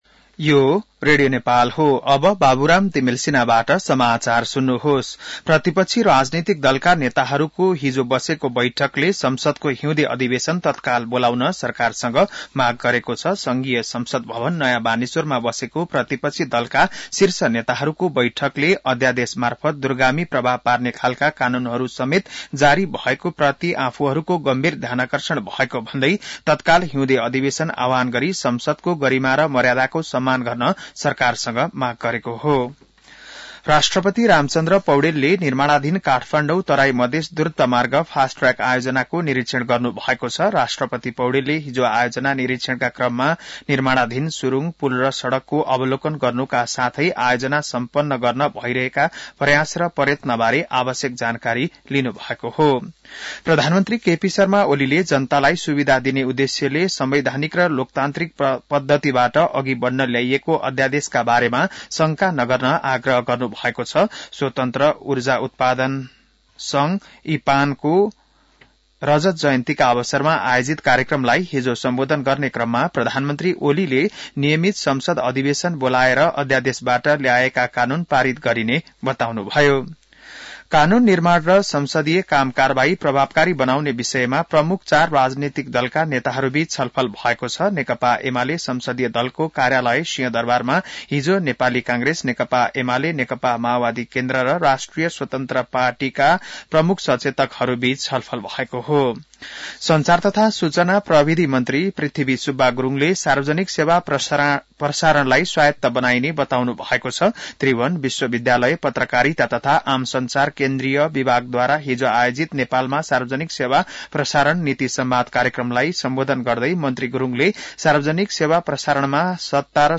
An online outlet of Nepal's national radio broadcaster
बिहान १० बजेको नेपाली समाचार : ६ माघ , २०८१